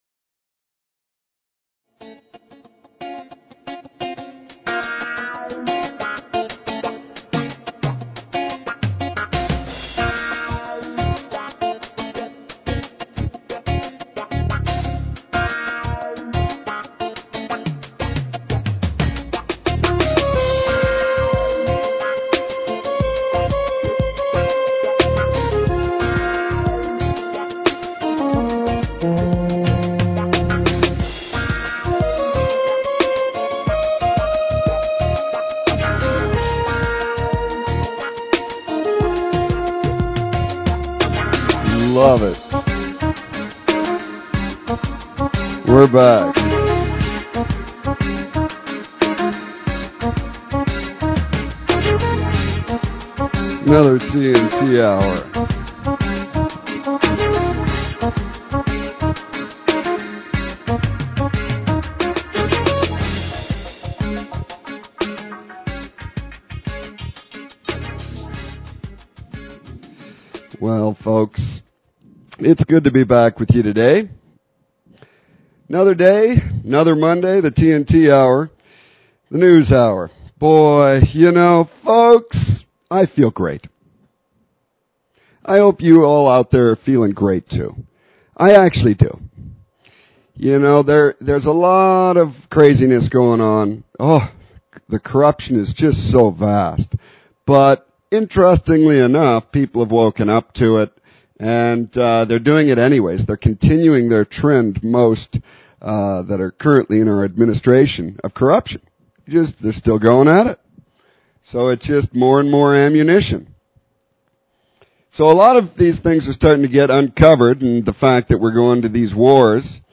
Talk Show Episode, Audio Podcast, The_TNT_Hour and Courtesy of BBS Radio on , show guests , about , categorized as